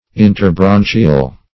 Interbranchial \In`ter*bran"chi*al\, a.